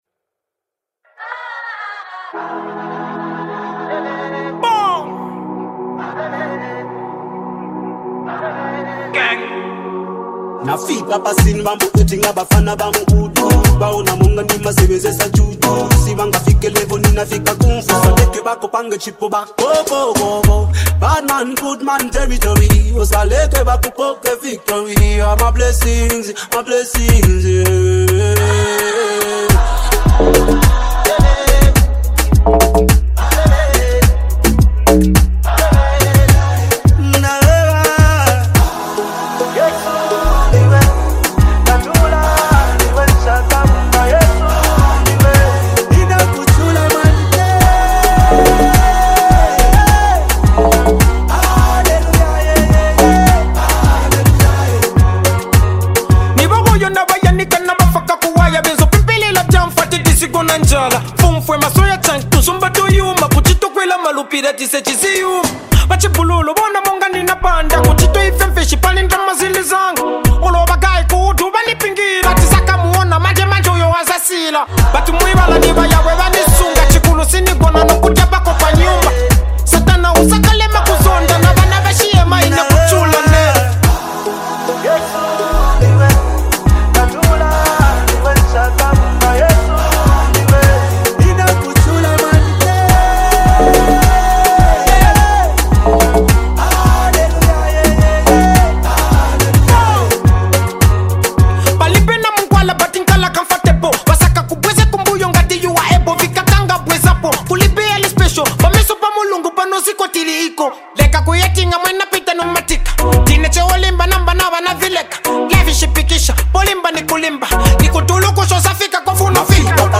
signature soulful vocals and melodic delivery
spiritual anthem